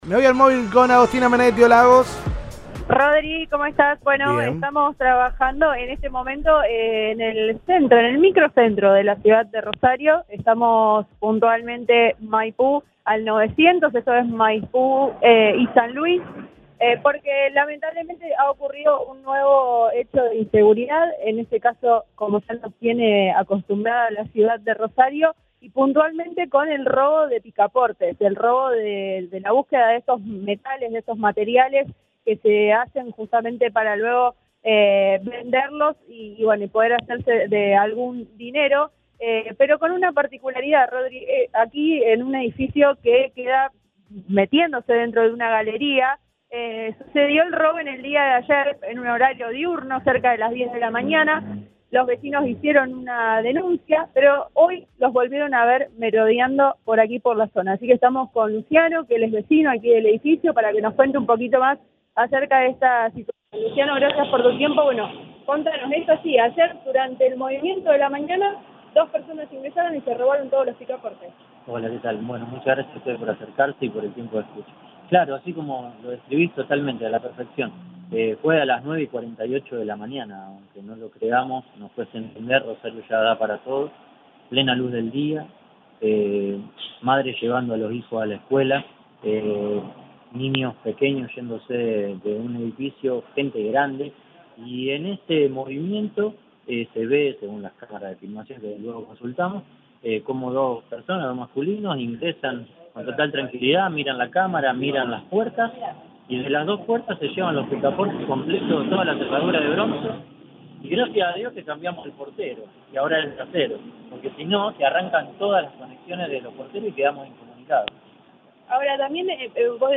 Lo denunció una de las personas que vive en el edificio asaltado al móvil de Cadena 3 Rosario.